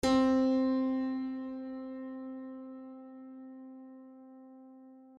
LoudAndProudPiano
c3.mp3